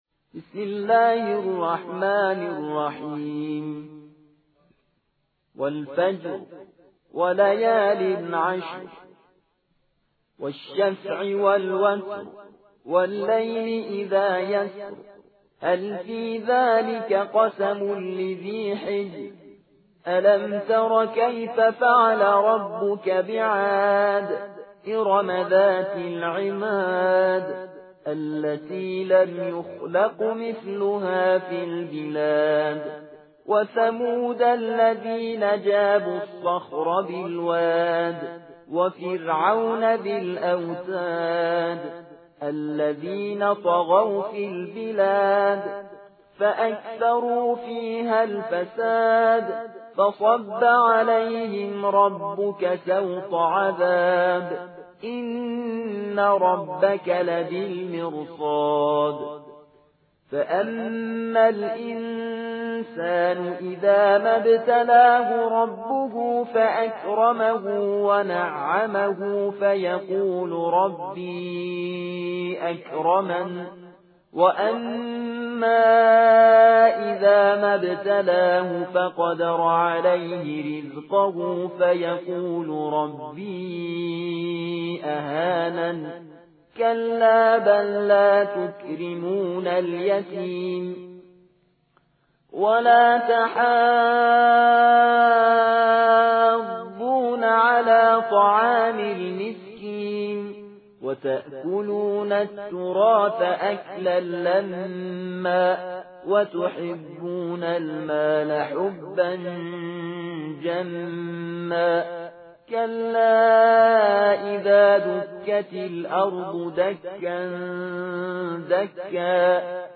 تلاوت ترتیل